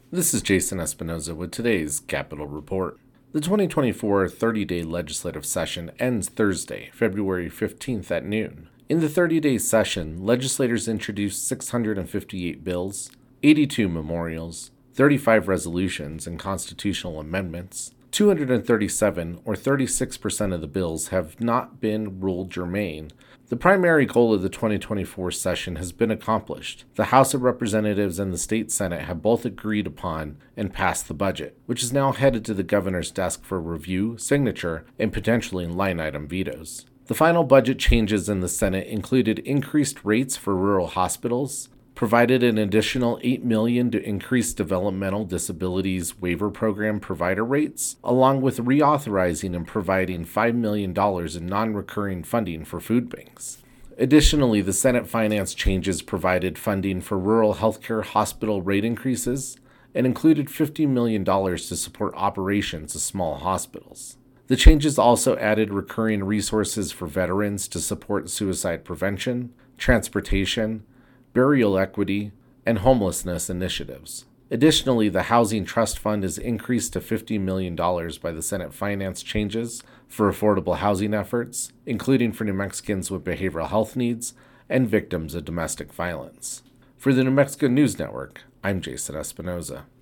capitol reports